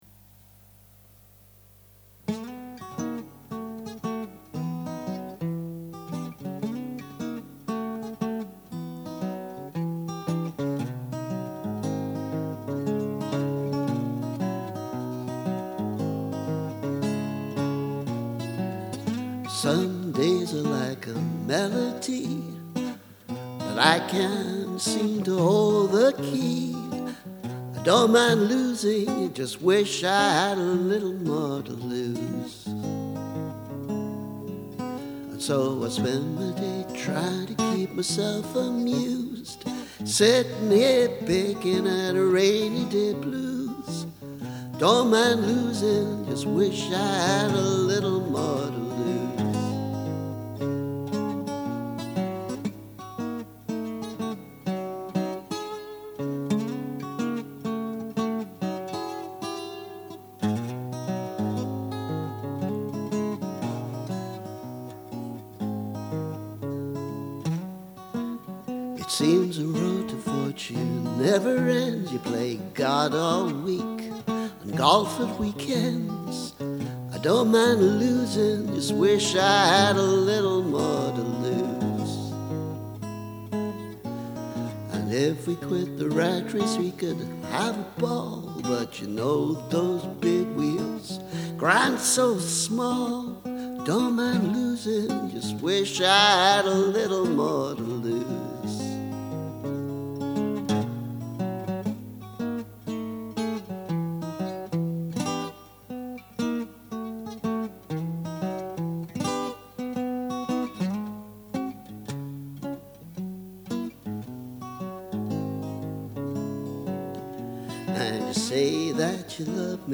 This is an older version with just basic guitar: